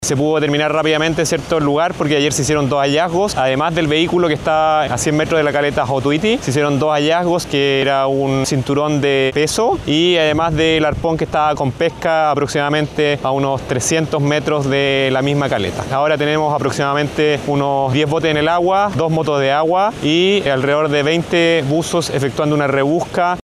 Tras el aviso de extravío, el Gobernador Marítimo, Miguel Bravo, señaló que tomó contacto con el fiscal del Ministerio Público, quien instruyó la realización de diligencias paralelas con el objetivo de esclarecer si la persona había ingresado efectivamente al mar en solitario, en qué condiciones se produjo dicho ingreso y el lugar probable en el que podría encontrarse.